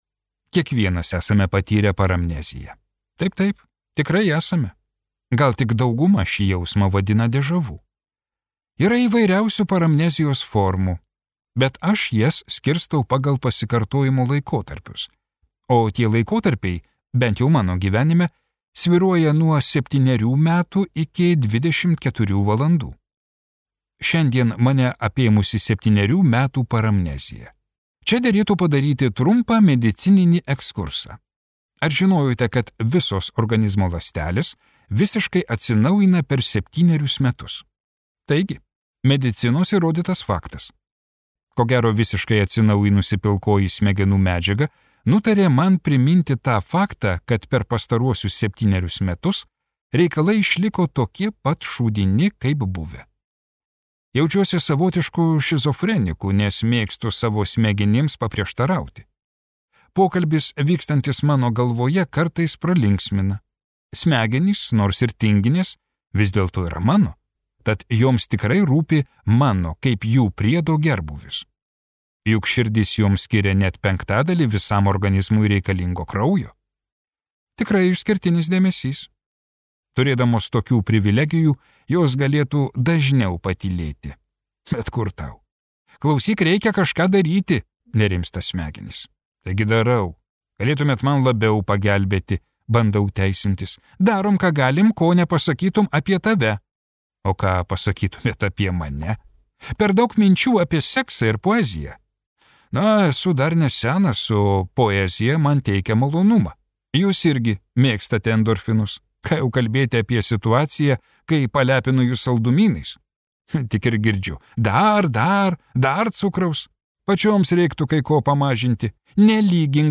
Zombis Lozorius | Audioknygos | baltos lankos